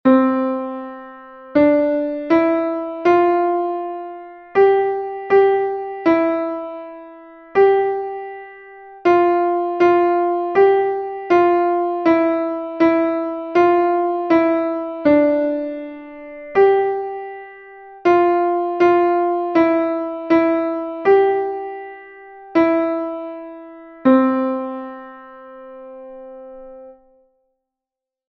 Here, there are two exercises in a 2/4 and 3/4 time signatures.
keeping the beat exercise 1